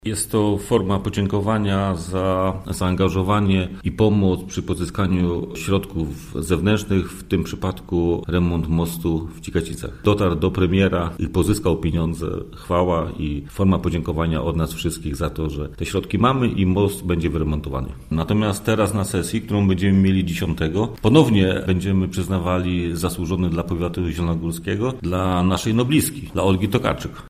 – Przypomnę, że kilka tygodni temu tytuł ten nasza rada przyznała posłowi Jerzemu Maternie – mówi Mirosław Andrasiak, członek zarządu powiatu zielonogórskiego.